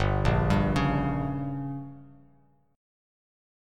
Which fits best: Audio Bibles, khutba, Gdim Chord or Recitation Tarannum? Gdim Chord